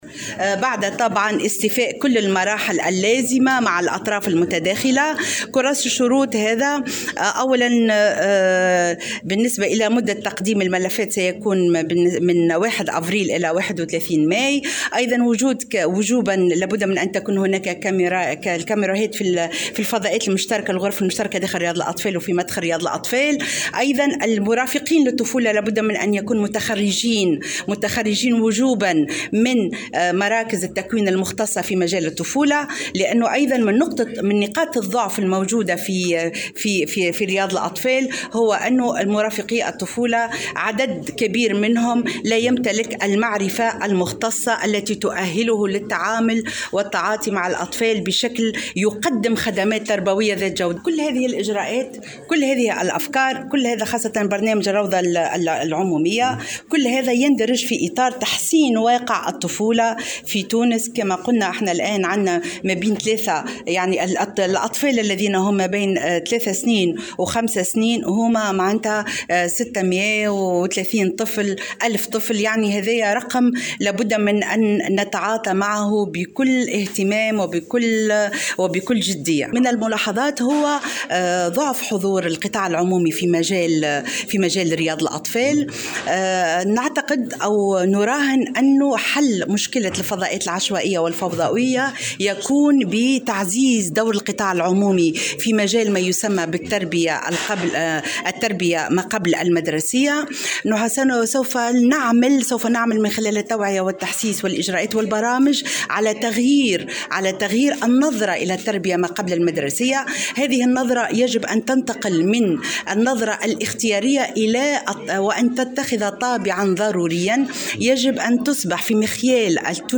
أعلنت وزيرة المرأة والاسرة و الطفولة وكبار السن، آمال بالحاج موسى، خلال ندوة صحفية عقدتها، اليوم الجمعة، أن الوزارة قد أصدرت كراس شروط جديد لرياض الأطفال يقع طباعته حاليا وسيتم نشره قريبا.